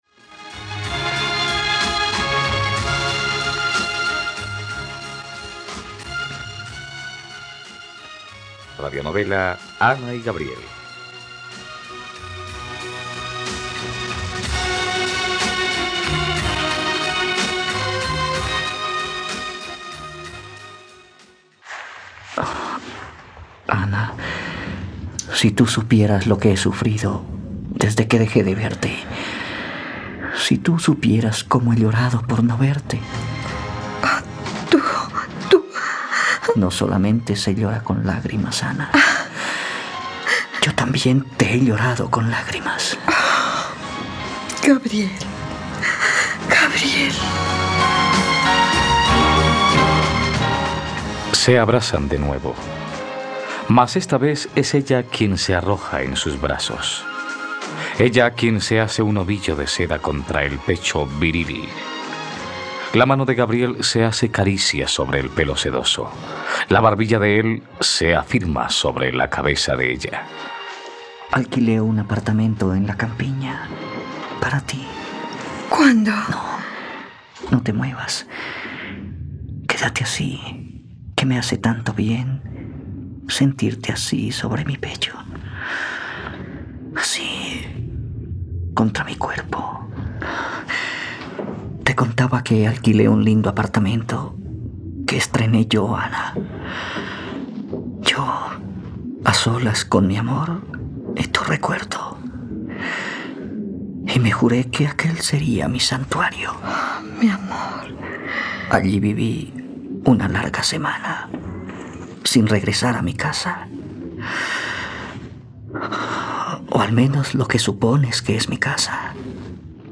..Radionovela. Escucha ahora el capítulo 79 de la historia de amor de Ana y Gabriel en la plataforma de streaming de los colombianos: RTVCPlay.